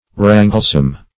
Search Result for " wranglesome" : The Collaborative International Dictionary of English v.0.48: Wranglesome \Wran"gle*some\, a. Contentious; quarrelsome.